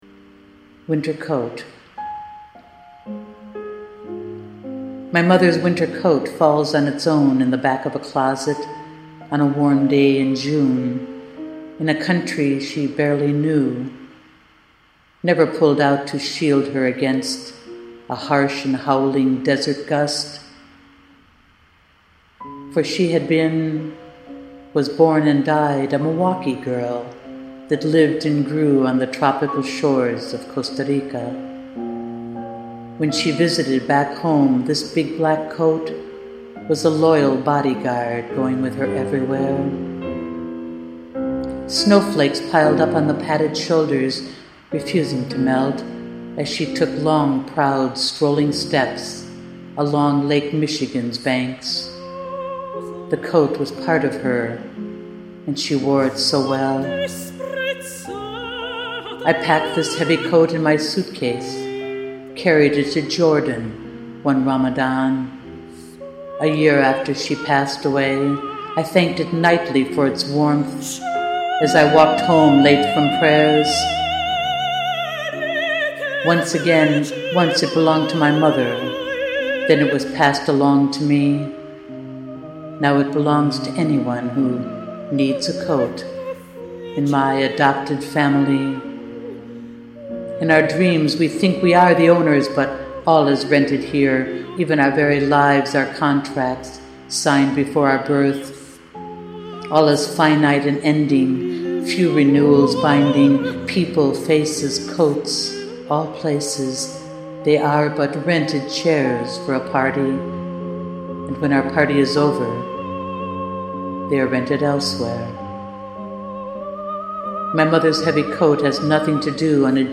The background is great.
Opera singing!
Winter Coat Live.mp3